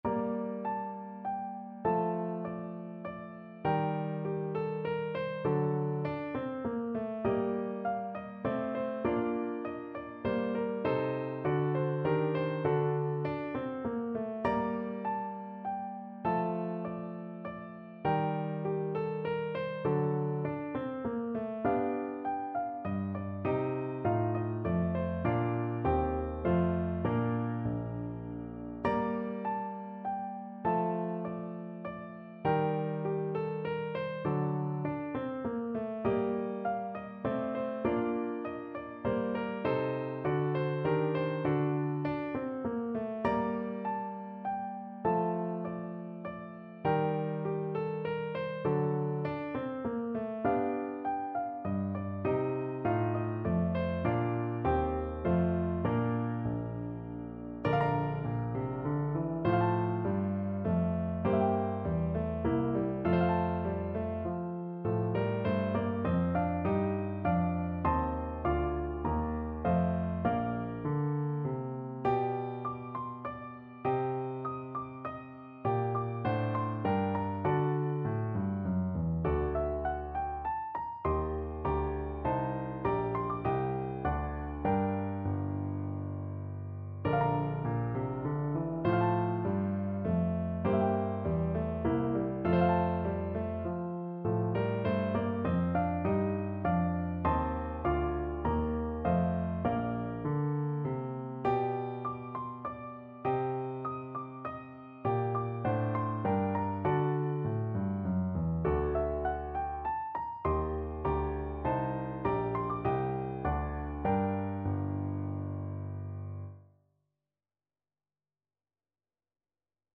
Free Sheet music for Piano Four Hands (Piano Duet)
3/4 (View more 3/4 Music)
Moderato =100
Classical (View more Classical Piano Duet Music)